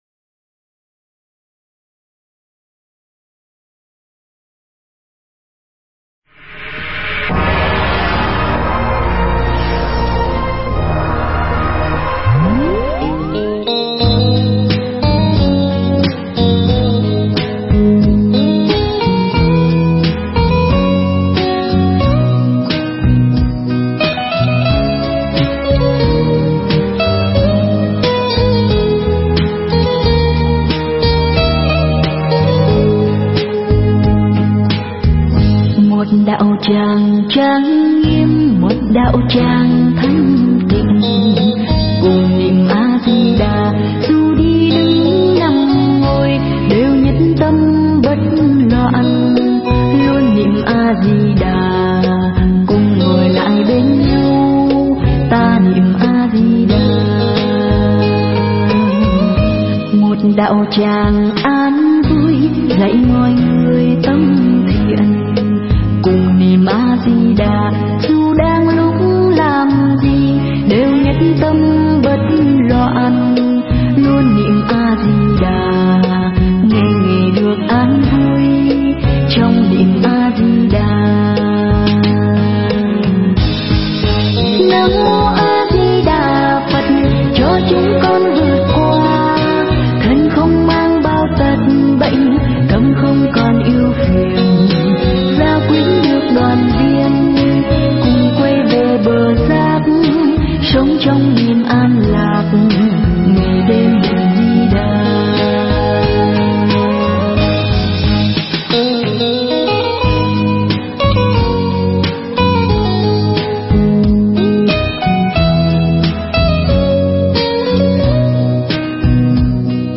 Mp3 Pháp Thoại Kinh Nhất Dạ Hiền Giả Phần 1